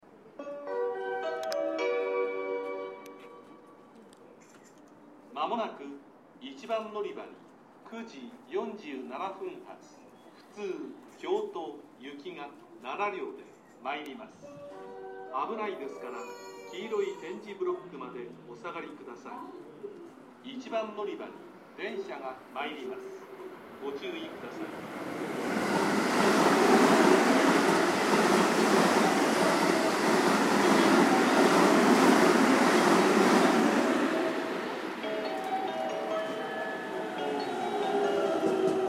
スピーカーの数は多めで位置も低めなので収録がしやすいです。音量もほかの駅と比べて大きめ ですね。
接近放送普通　京都行き接近放送です。